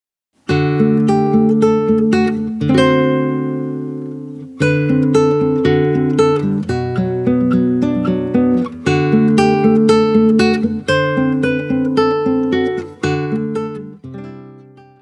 violão de 6 cordas